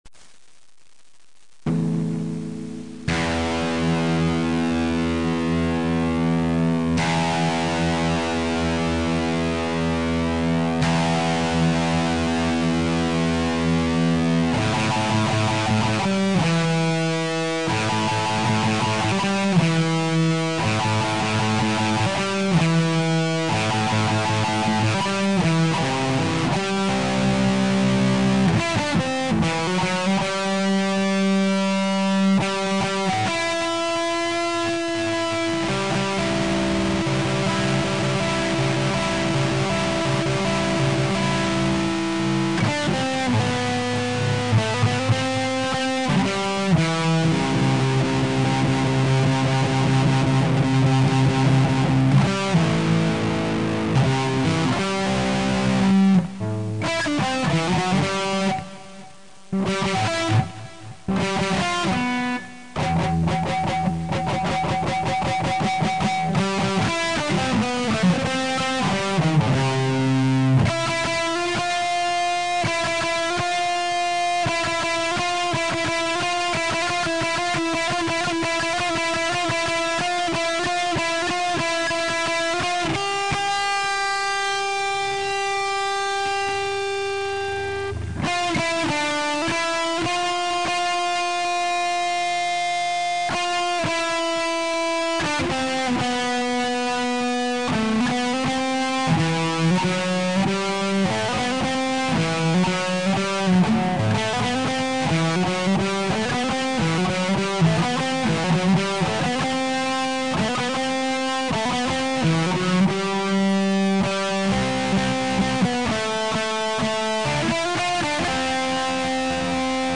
Ah, good ole electric geetar!
...more electric guitar